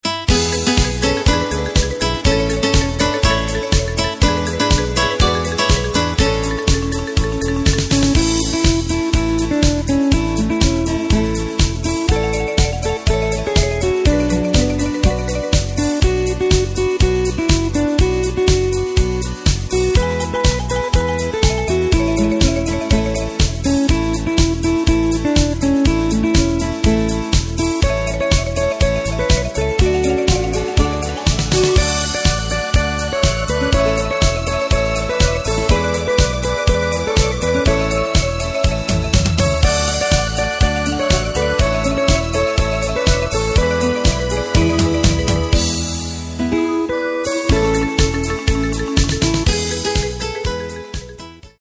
Takt:          4/4
Tempo:         122.00
Tonart:            A
Schlager aus dem Jahr 1975!